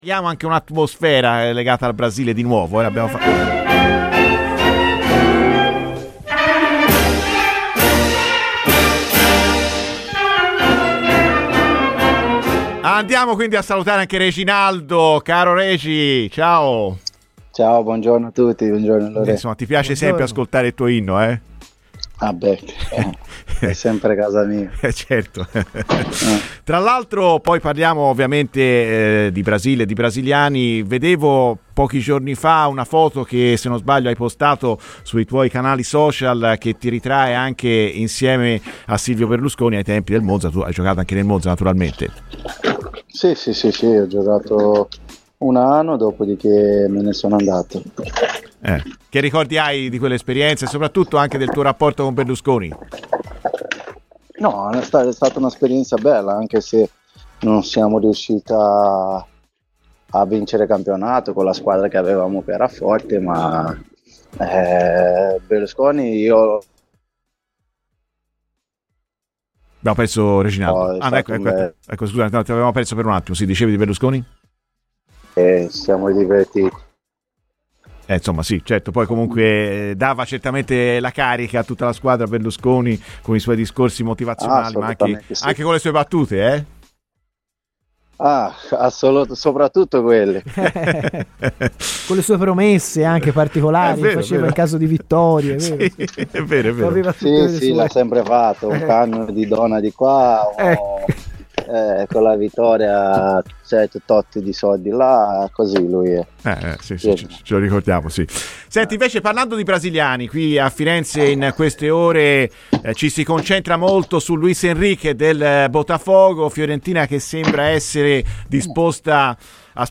Per parlare di un brasiliano accostato alla Fiorentina, su Radio FirenzeViola ecco un ex viola carioca come Reginaldo.